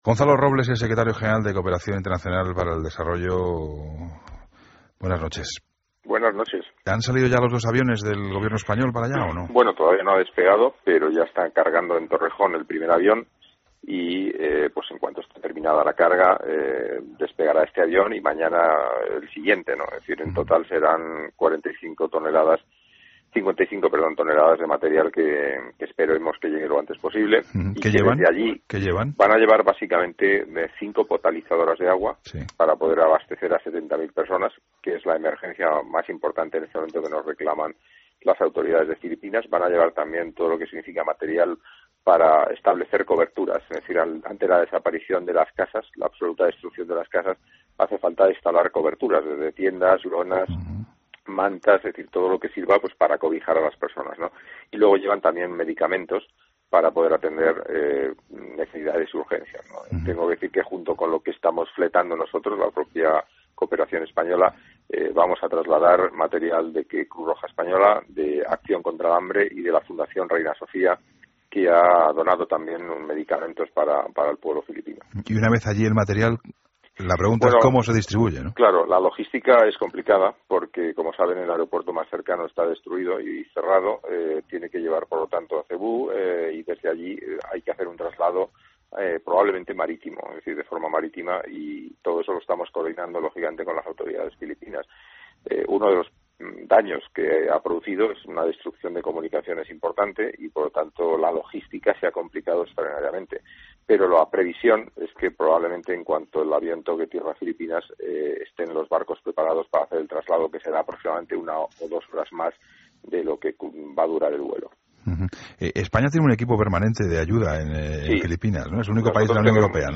Entrevistas en La Linterna
Secretario general de COoperación Internacional para el Desarrollo